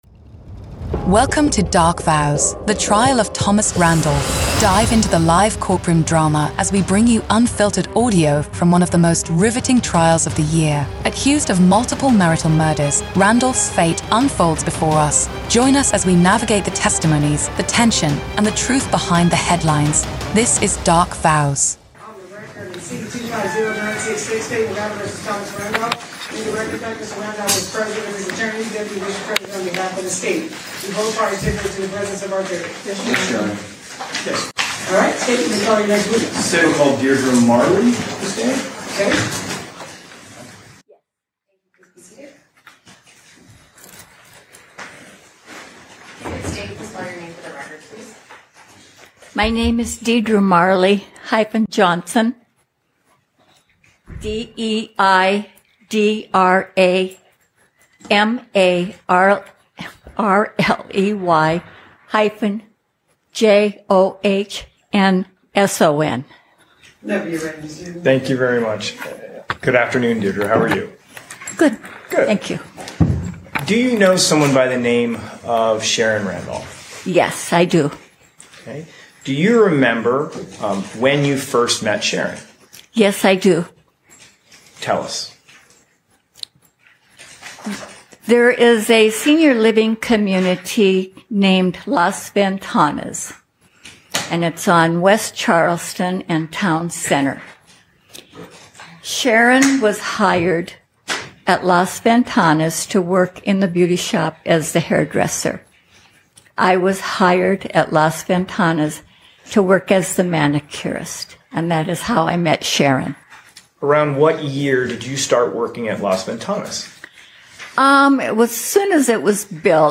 Every episode beams you directly into the heart of the courtroom with raw, unedited audio from testimonies, cross-examinations, and the ripple of murmurs from the gallery.
We accompany the live audio with expert legal insights, breaking down the day's events, the strategies in play, and the potential implications of each revelation.